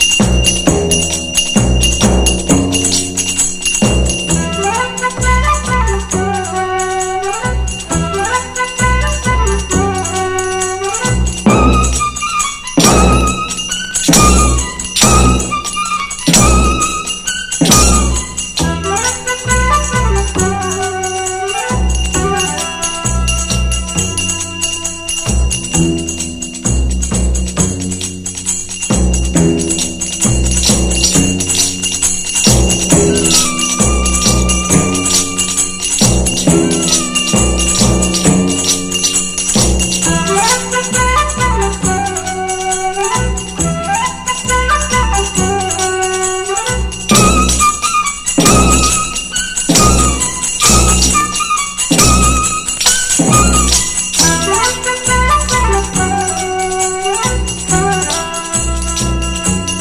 カリビアン～サンバ～スキャットなどなどやってます！
グルーヴィーなソウル風ナンバーから、エキゾ～カリプソ等も収録で、欲張りな方にも満足して貰えるはず！
ミュージカルらしい演出が派手目でキャッチー！